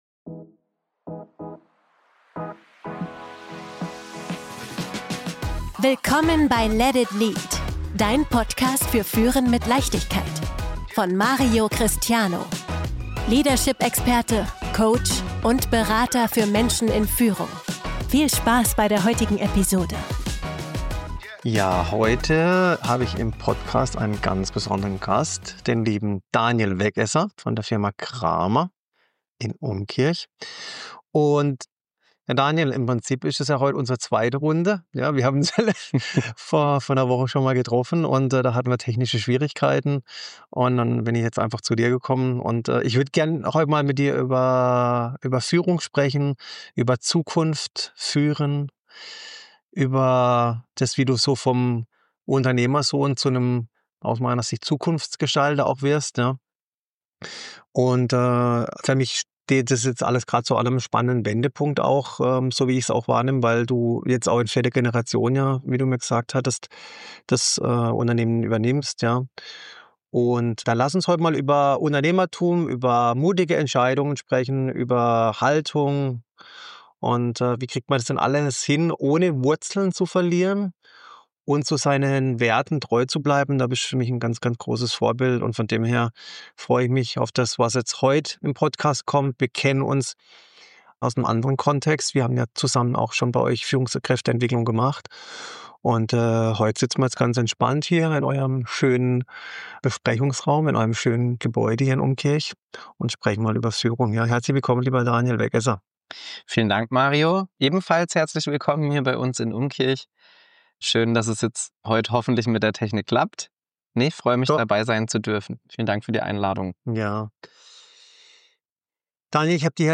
Tradition trifft Transformation - Interview